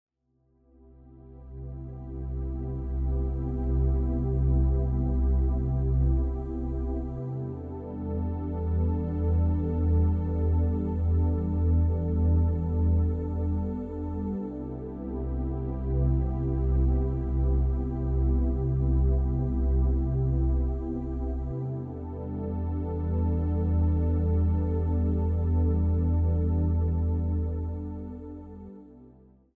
Tranquil/Ambient Music Sample